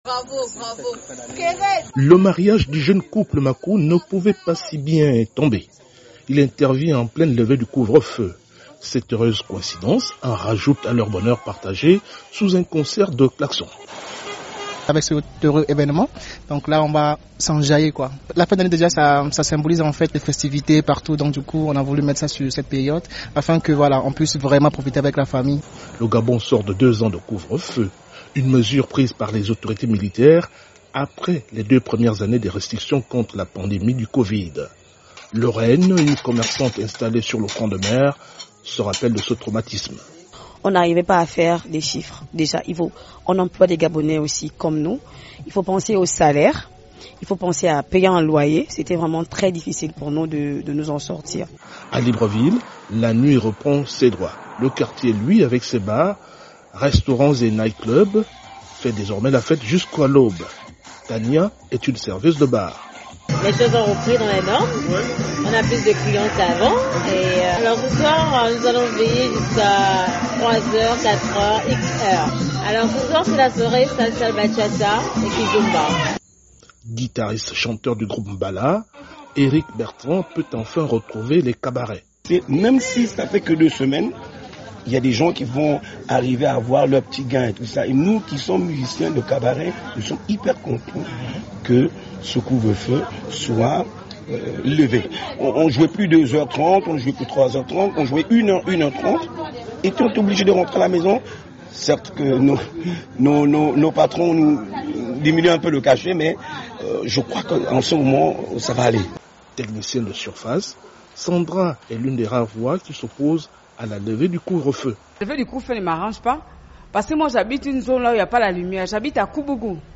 Au Gabon, après plus de deux ans sous cloche, le pays retrouve la liberté. Le gouvernement de transition a décidé de lever le couvre-feu jusqu’à nouvel ordre. À Libreville, la vie reprend des couleurs à l’approche des fêtes de fin d’année.